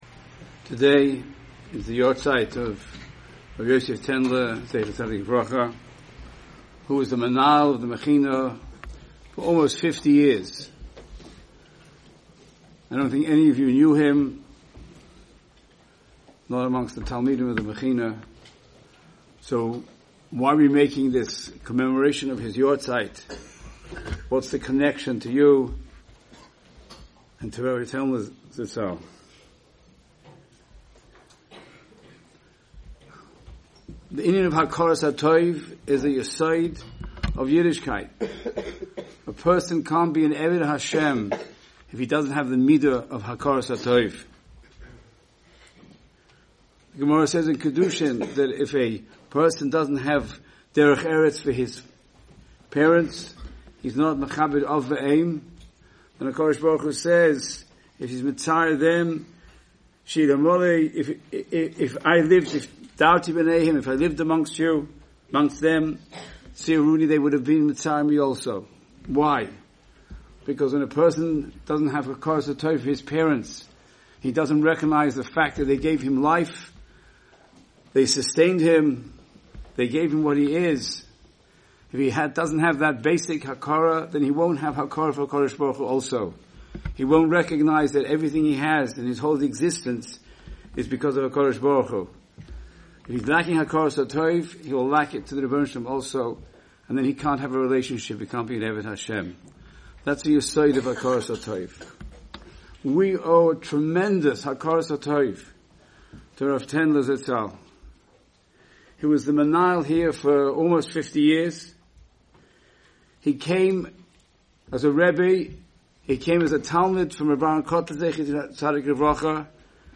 said divrei hisorirus in the Mechina, l’iluy nishmaso.